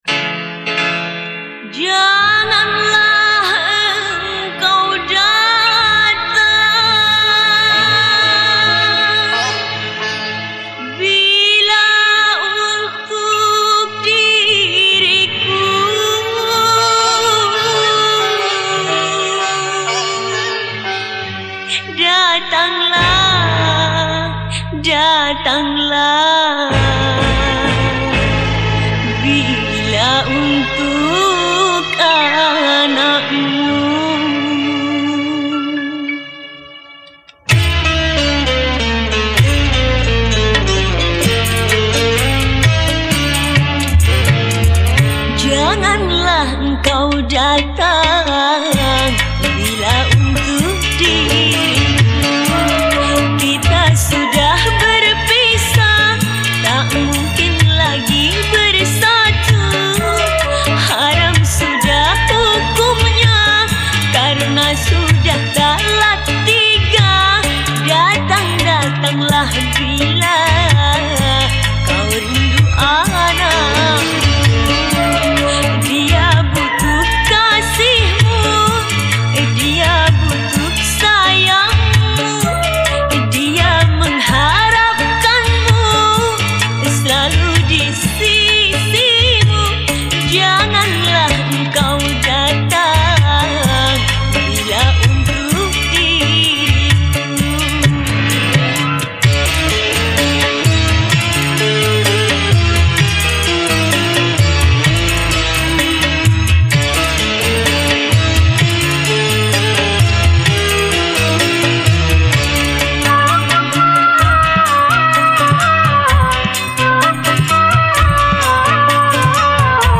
Instrumen                                     : Vokal